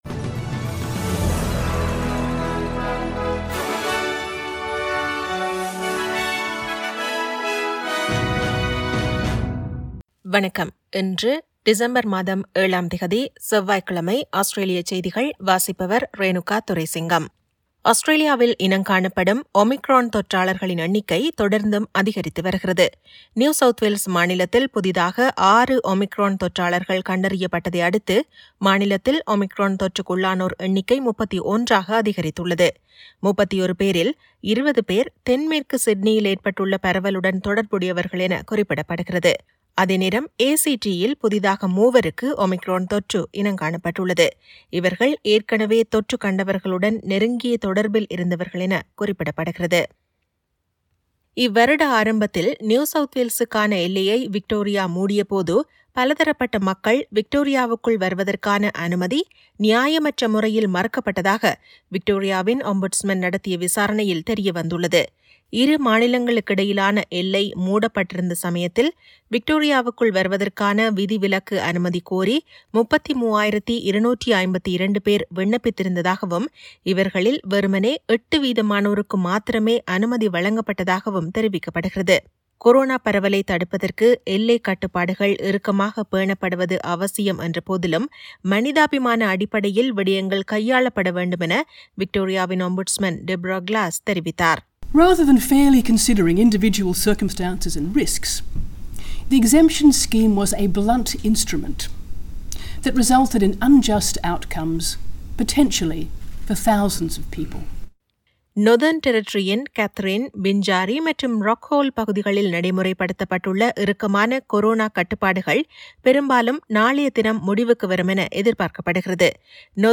Australian news bulletin for Tuesday 07 December 2021.